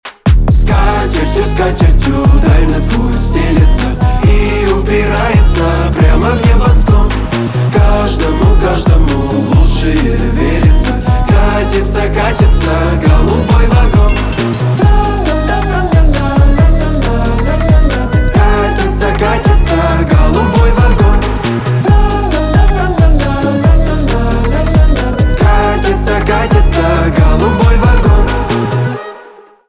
поп
cover , битовые , басы , крутые , качающие
аккордеон